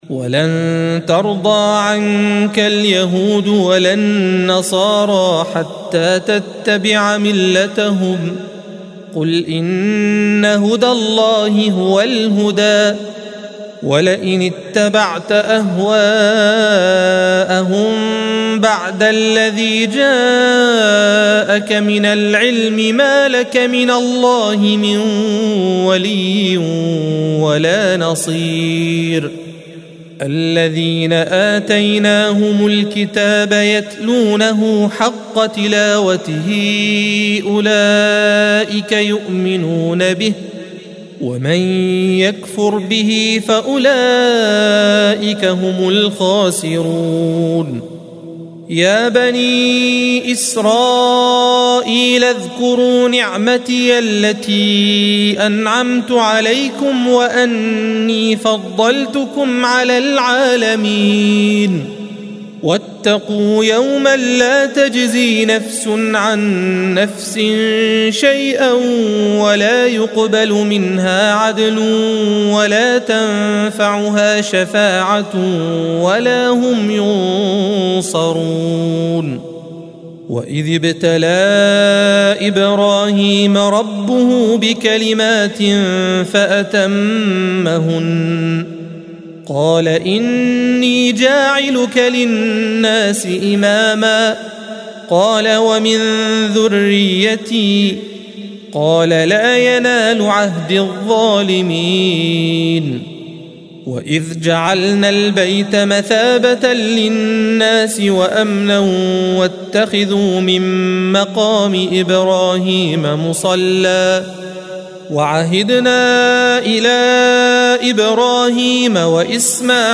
الصفحة 19 - القارئ